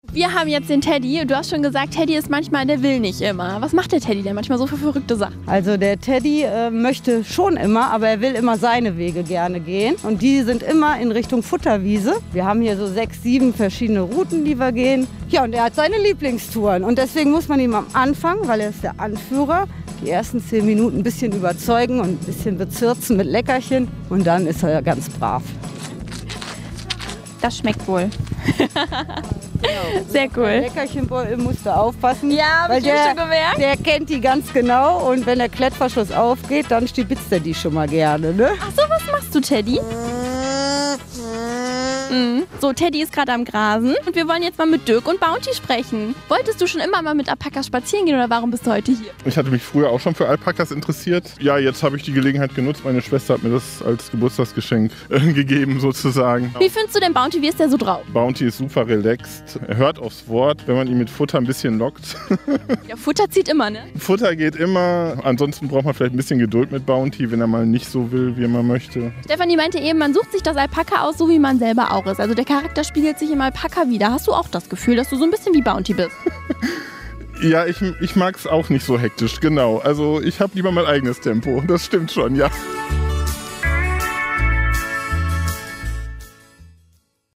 Bericht Alpaka Ranch Remscheid